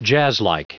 Prononciation du mot jazzlike en anglais (fichier audio)
Prononciation du mot : jazzlike